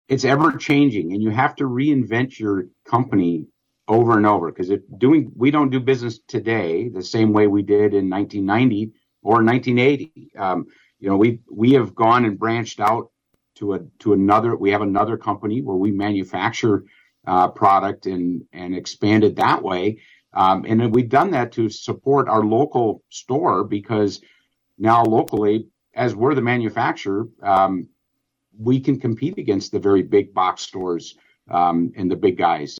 Randy Meppelink, the Ottawa County Commissioner who owns Great Lakes Window Coverings on Holland’s North Side, said in a Tuesday appearance on “WHTC Talk of the Town” that being adaptable to the winds of change is essential for small local businesses to survive.